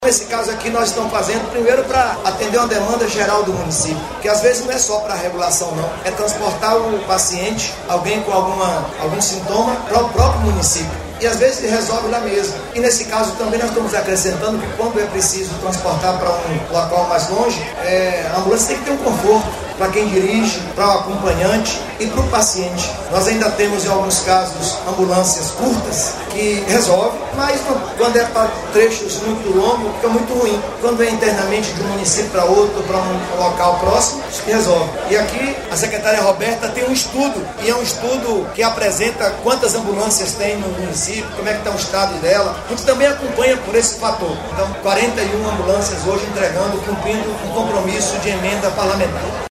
🎙 Governador Jerônimo Rodrigues